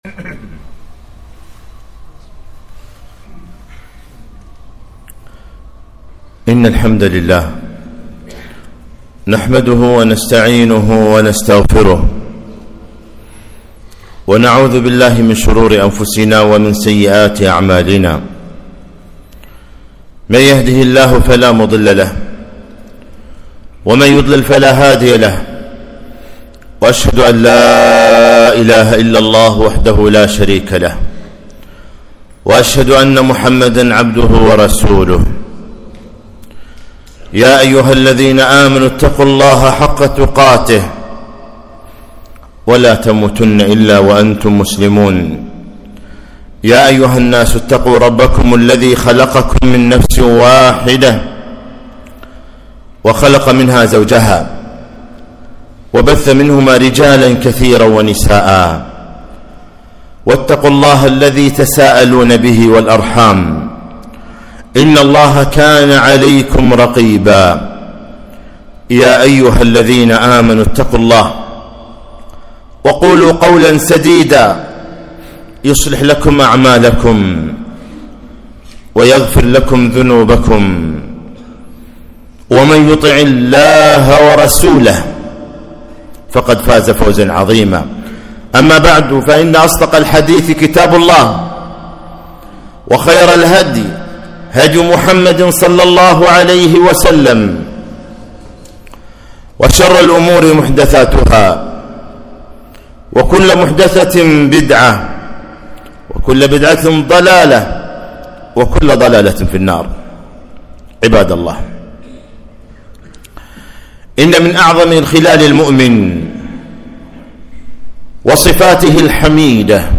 خطبة - الأ تغارون!!